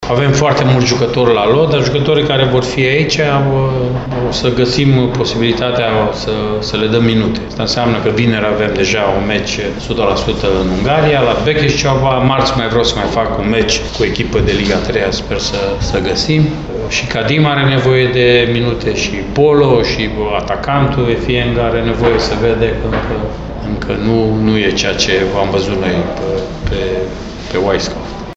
Tehnicianul arădenilor spune că va folosi pauza prilejuită de meciurile echipelor naţionale pentru a le acorda mai multe minute jucătorilor care revin după accidentări sau celor care au ajuns mai târziu la Arad şi a confirmat că UTA va susţine un amical, vinerea viitoare, la Beckescaba: